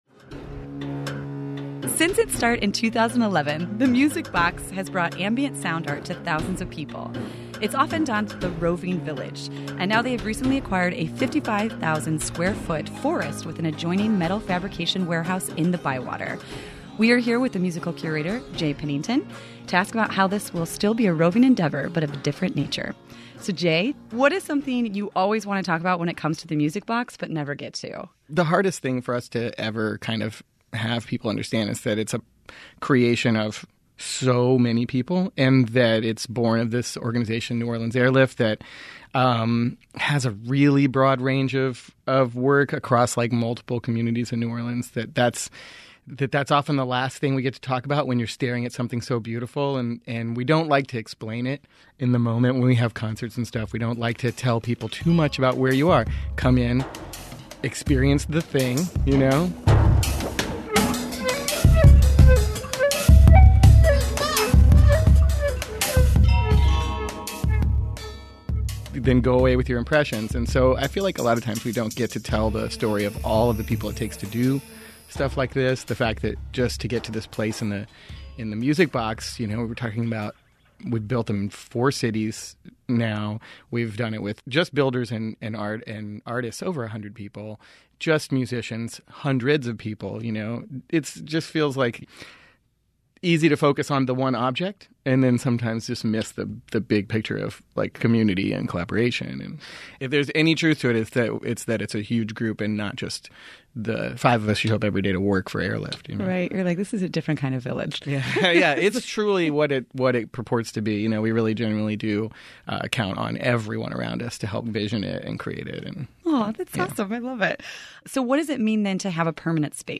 WWNO interview